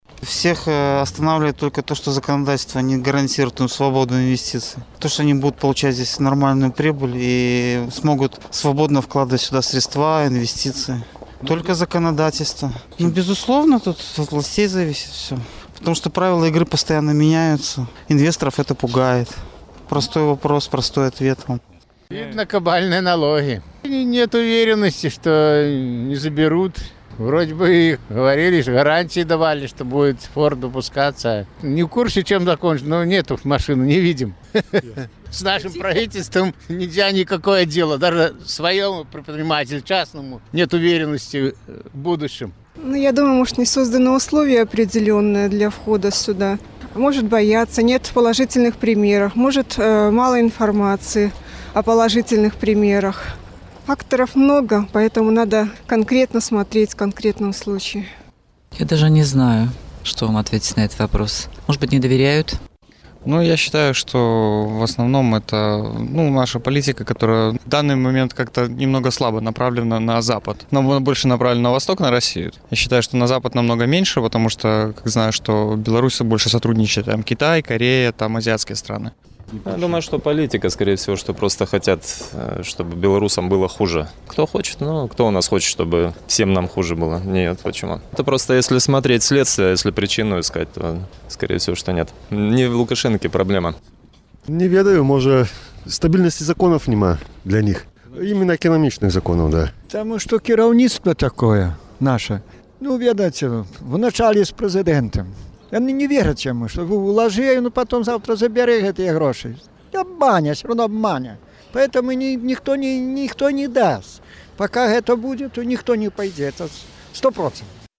Апытаньне ў Горадні